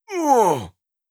08. Damage Grunt (Male).wav